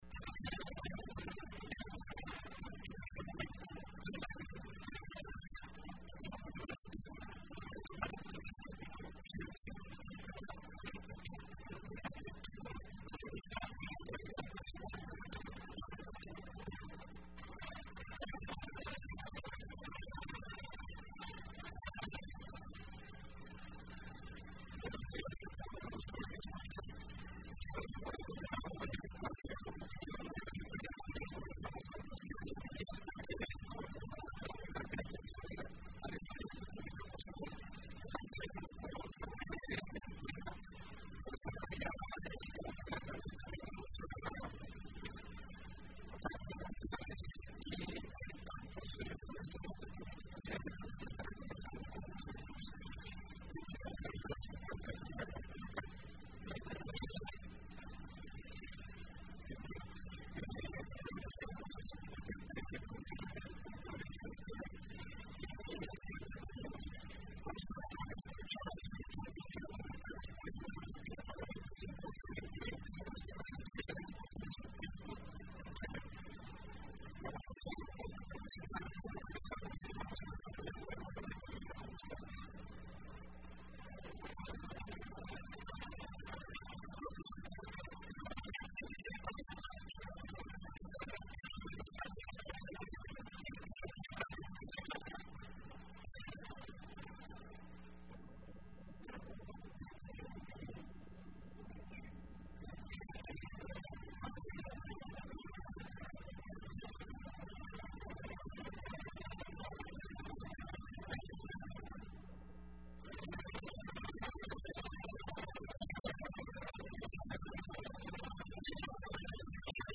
Entrevista Opinión Universitaria (2 febrero 2015): Labor que desarrolla la Unidad de Atención al Estudiante con Discapacidad de la Universidad de El Salvador.